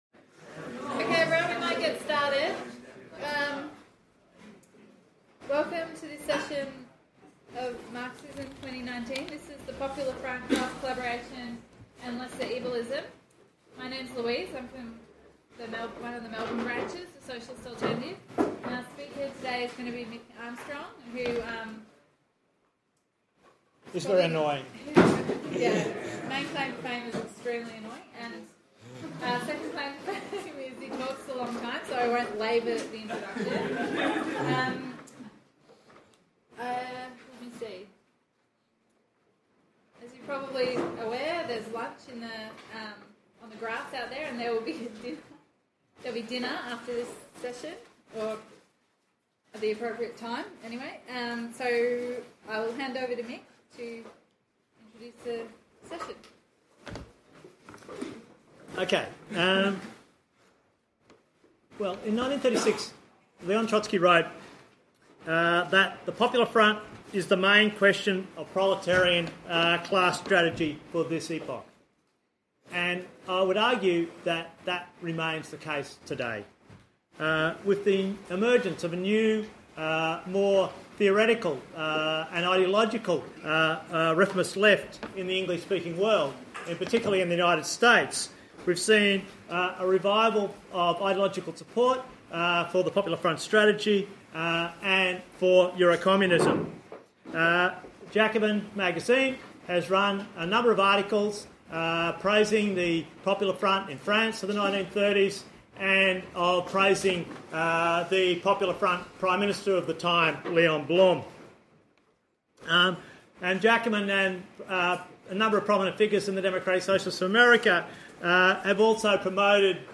Marxism 2019